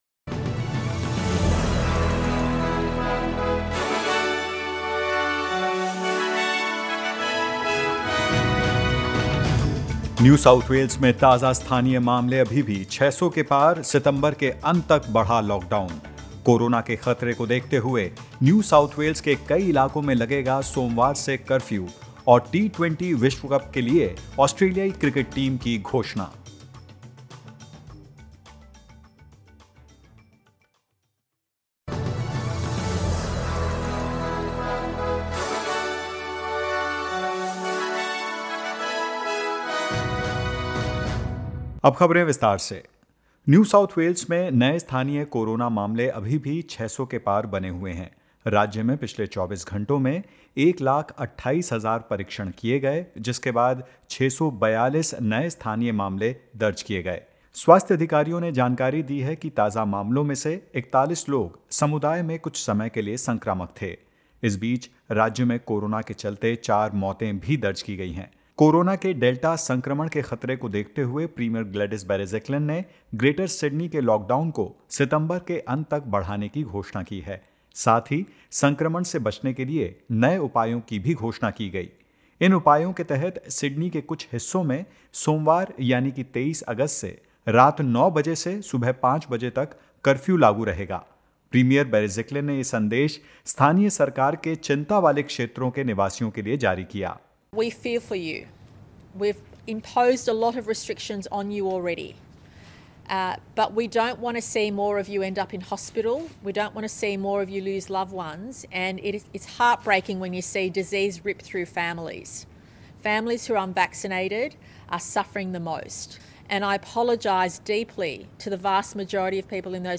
In this latest SBS Hindi News bulletin of Australia and India: Contact tracing underway in Victoria for one case in Shepparton; South Australia eases its border restrictions and more.